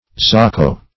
Search Result for " zocco" : The Collaborative International Dictionary of English v.0.48: Zocco \Zoc"co\, Zoccolo \Zoc"co*lo\, n. [It. fr. L. socculus.